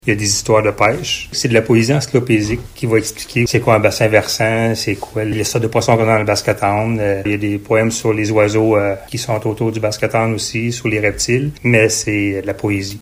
Une activité de rencontre d’auteurs a eu lieu, hier soir, à la bibliothèque J.R. L’Heureux à Maniwaki.